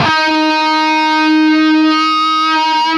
LEAD D#3 CUT.wav